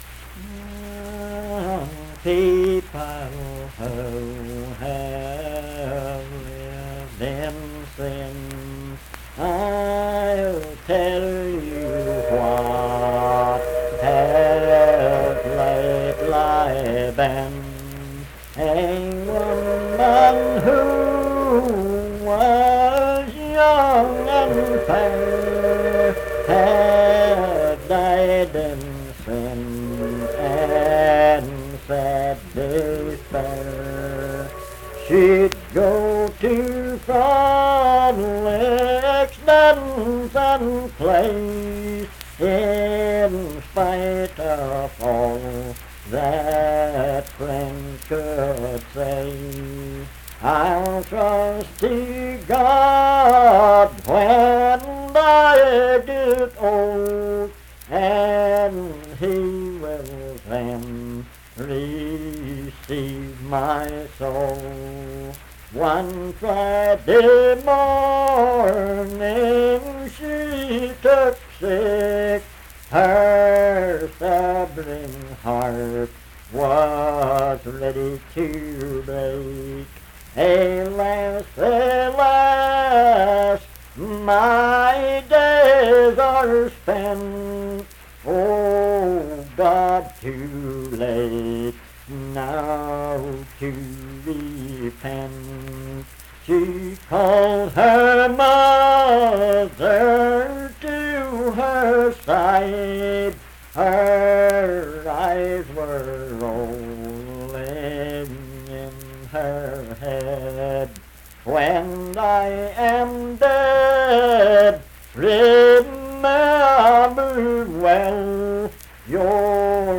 Unaccompanied vocal music
Performed in Dundon, Clay County, WV.
Hymns and Spiritual Music
Voice (sung)